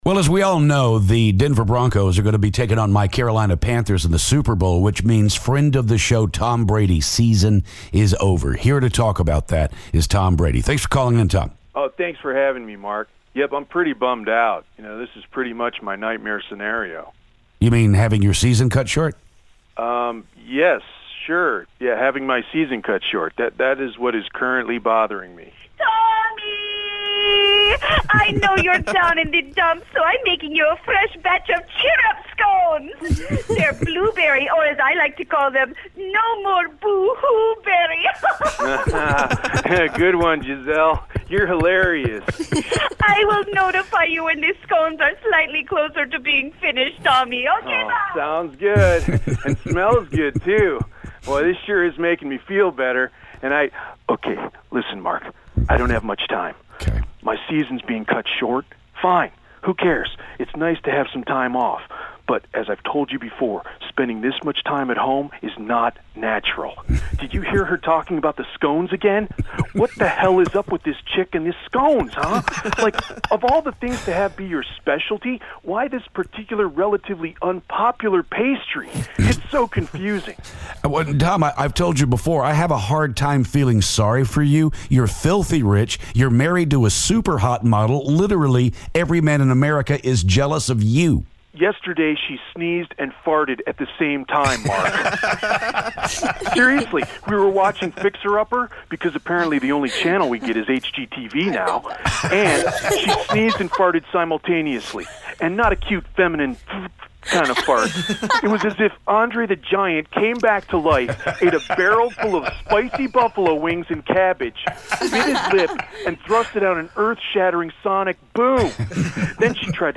Tom Brady calls to talk about losing to the Broncos.